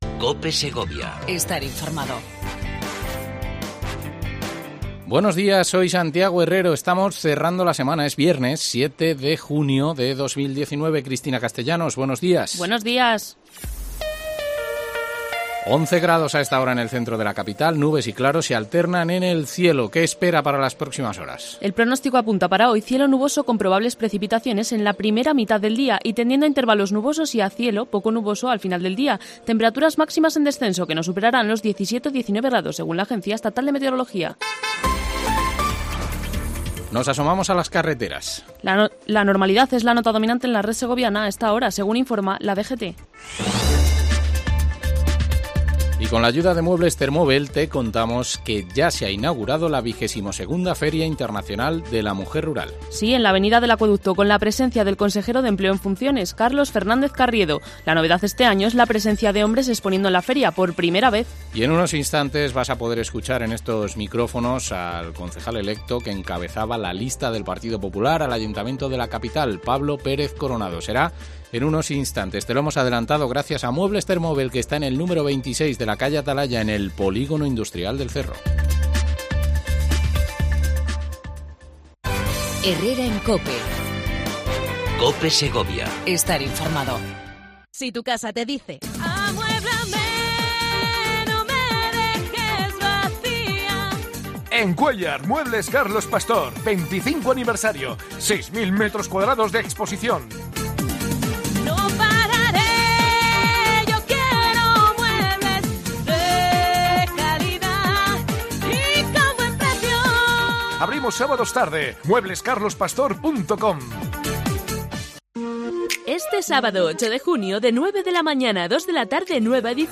AUDIO: Entrevista a Pablo Pérez Coronado, Concejal electo que encabezaba las listas del PP a la Alcaldía de Segovia.